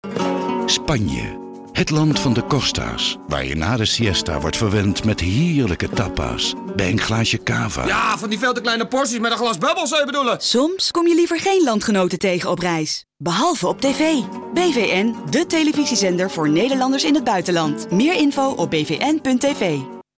De nieuwe campagne voor BVN Nederland, die bestaat uit 1 TV-spot en 3 radiospots, toont perfect aan dat Nederlanders liever geen landgenoten zien in het buitenland ... behalve op TV via BVN.